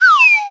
se_cancel.wav